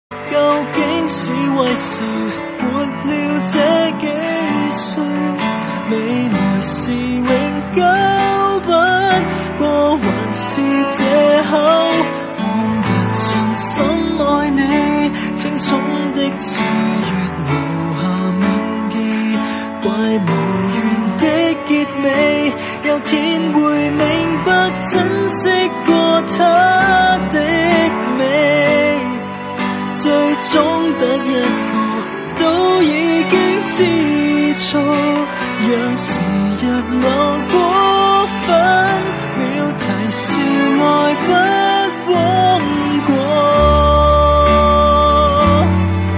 M4R铃声, MP3铃声, 华语歌曲 102 首发日期：2018-05-14 14:23 星期一